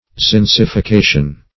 Search Result for " zincification" : The Collaborative International Dictionary of English v.0.48: Zincification \Zinc`i*fi*ca"tion\, n. The act or process of applying zinc; the condition of being zincified, or covered with zinc; galvanization.
zincification.mp3